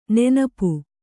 ♪ nenapu